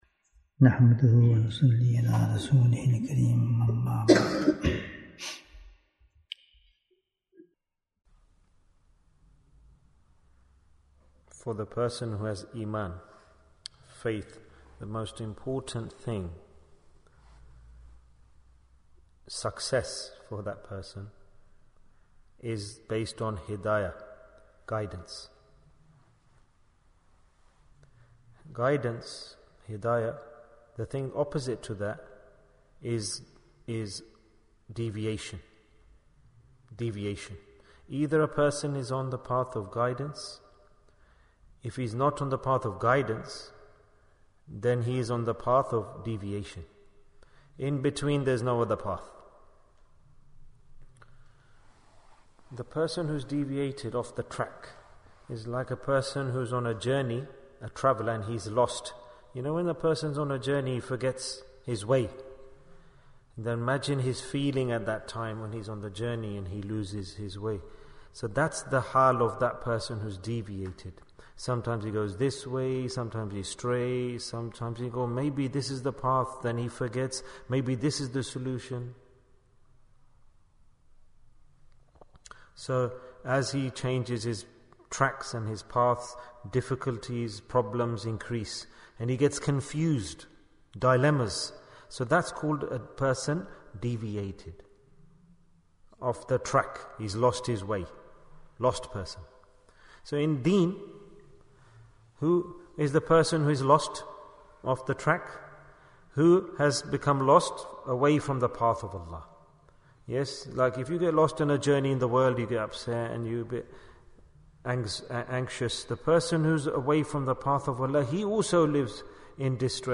Bayans Clips Naat Sheets Store Live The Path of Guidance Bayan, 59 minutes 15th April, 2023 Click for Urdu Download Audio Comments The Path of Guidance A person's success is based on guidance.